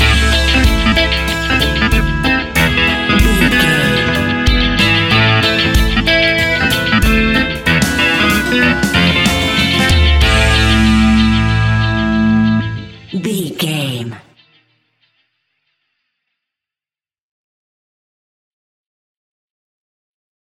Aeolian/Minor
instrumentals
laid back
chilled
off beat
drums
skank guitar
hammond organ
transistor guitar
percussion
horns